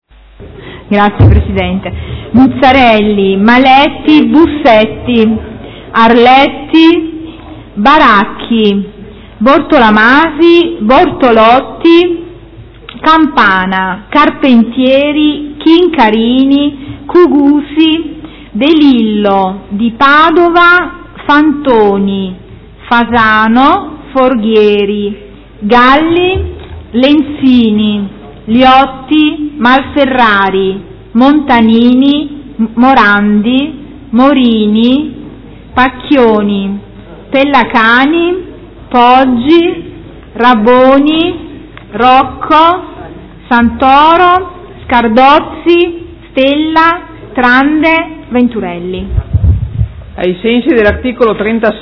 Seduta del 9/06/2016 Appello.